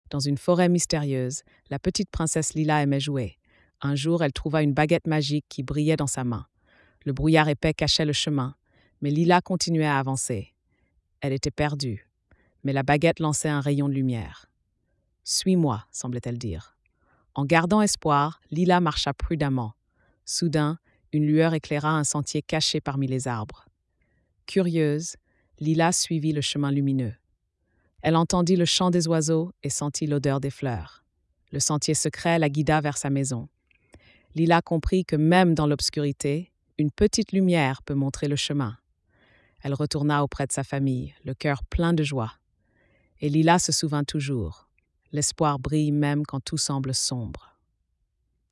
🎧 Lecture audio générée par IA